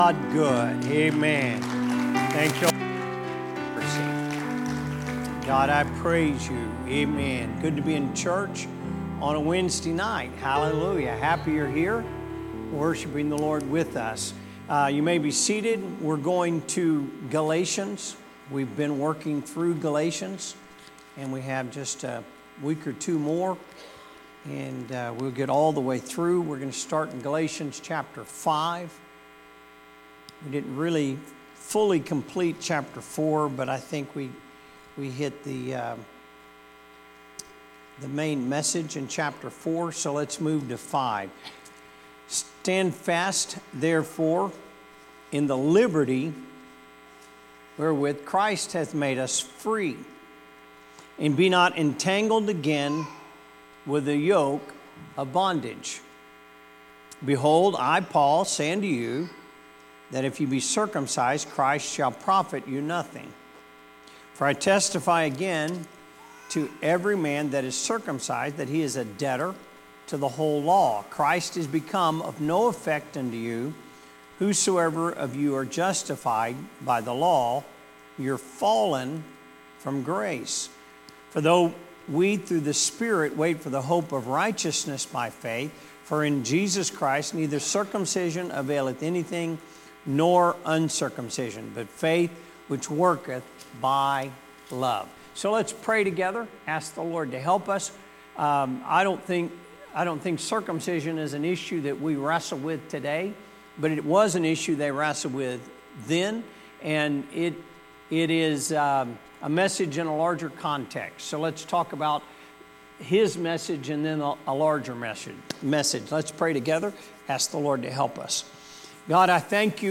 Sermons | Elkhart Life Church
Wednesday Service - Galations